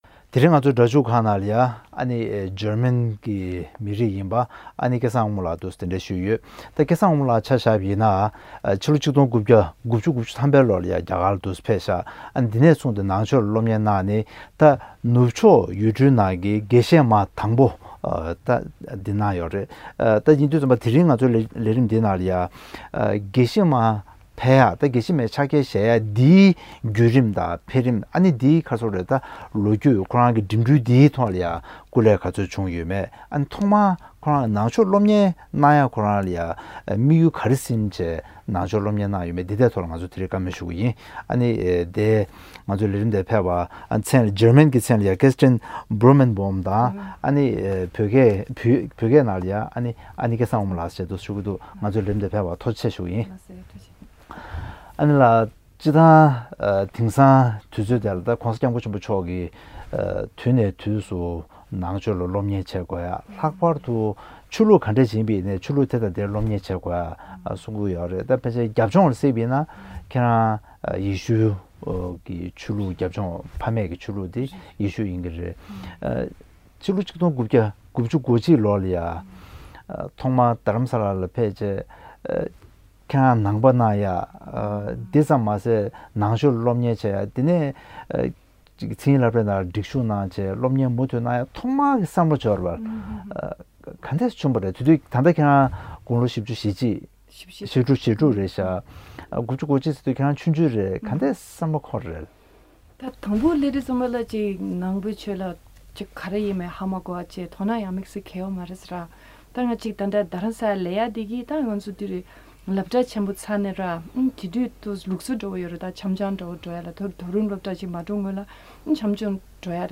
ཇར་མན་མི་རིགས་ཡིན་པ་བཙུན་མ་སྐལ་བཟང་དབང་མོ་ལགས་དང་གླེང་མོལ་ཞུས་པའི་དམིགས་བསལ་ལེ་ཚན།